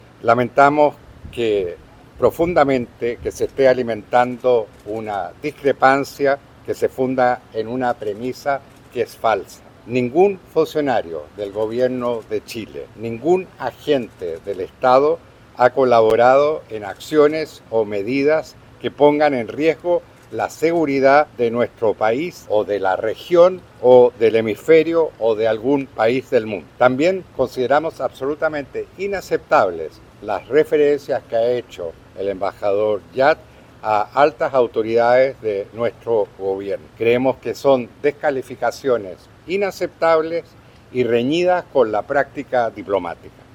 Tras la cita, el canciller Alberto van Klaveren entregó declaraciones en las que rechazó categóricamente los planteamientos realizados por el embajador estadounidense, calificando las acciones adoptadas por ese país como “inexplicables” y “arbitrarias”.